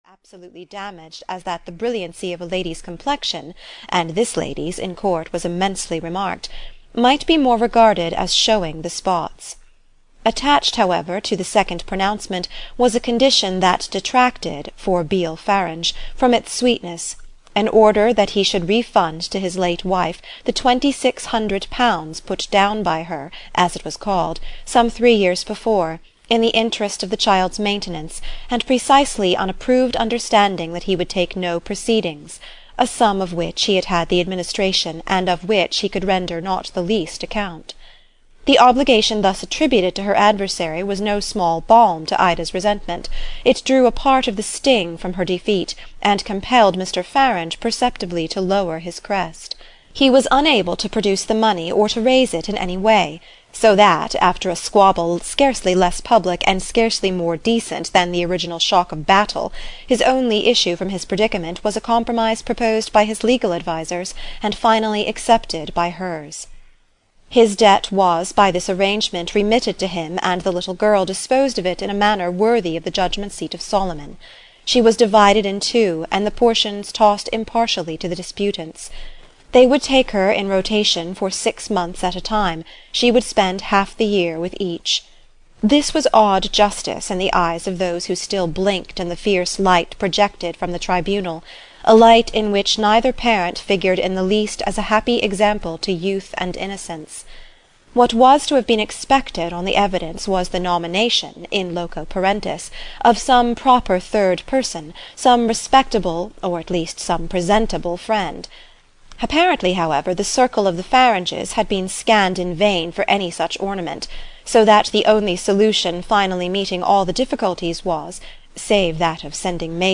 What Maisie Knew (EN) audiokniha
Ukázka z knihy